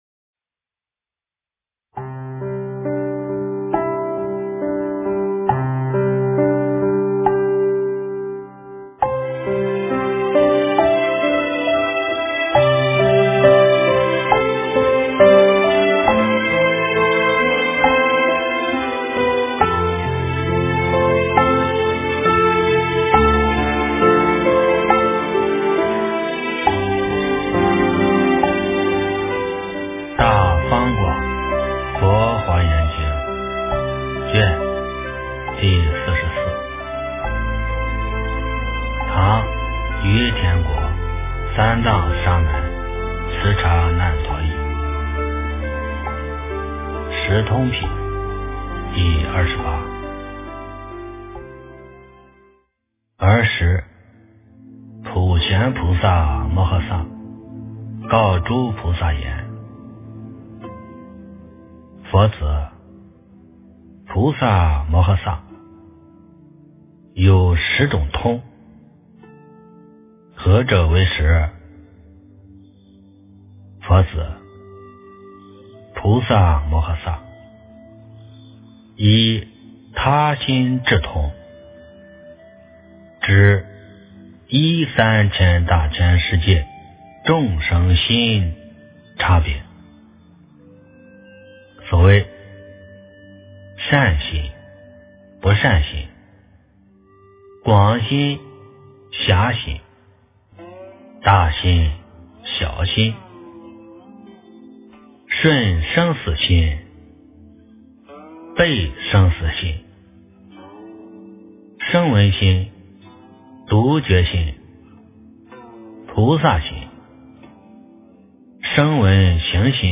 诵经
佛音